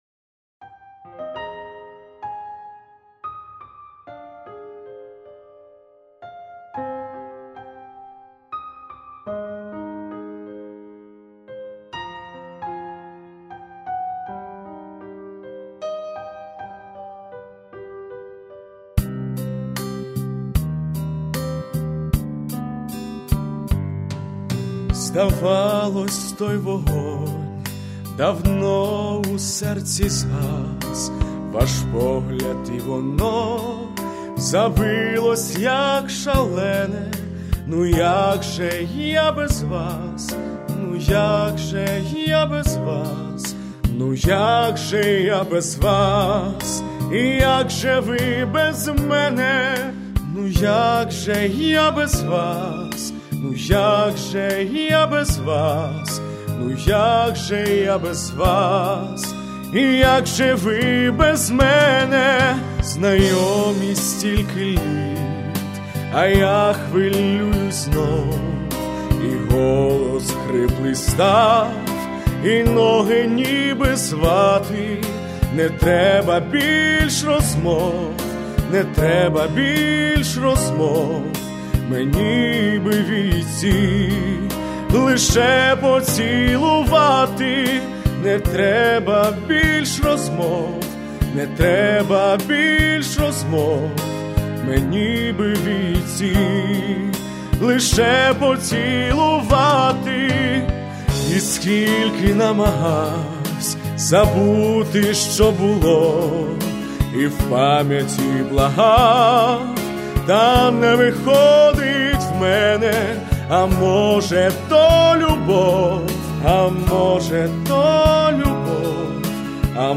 романс
сл. І . Гентоша